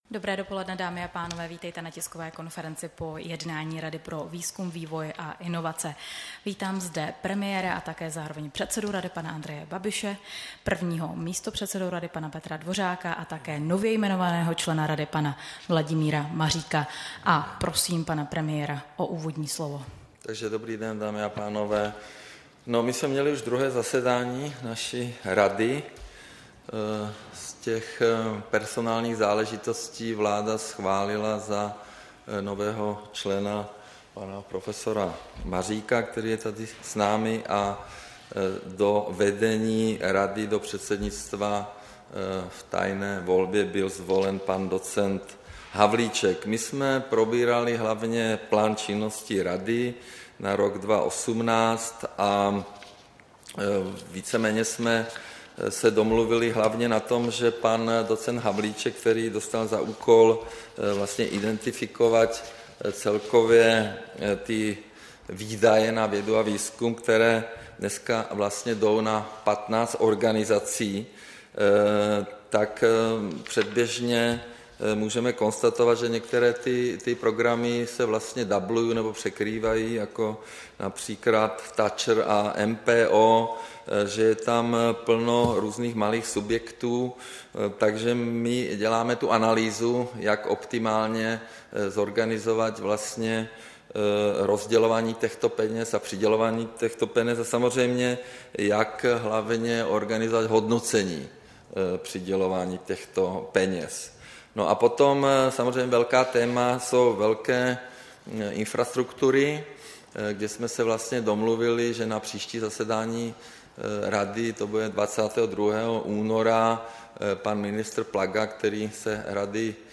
Tisková konference po jednání Rady pro výzkum, vývoj a inovace, 2. února 2018